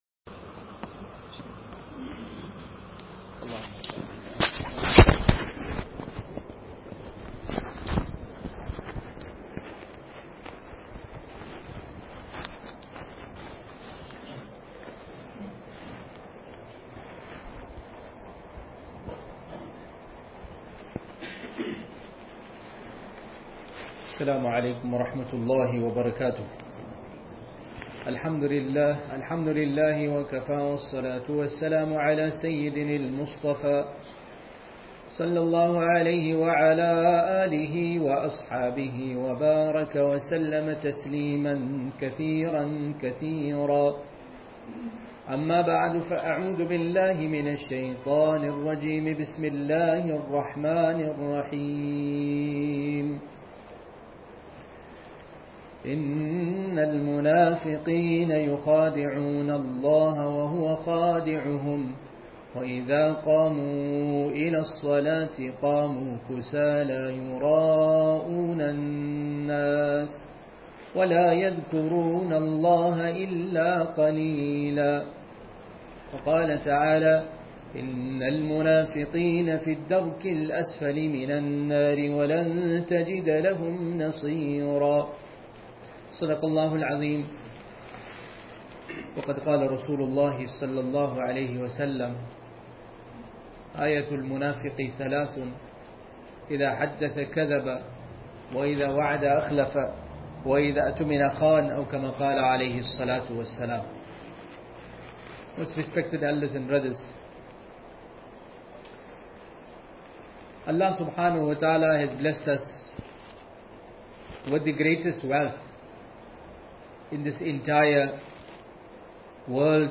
Jumua – Demat Islamic Centre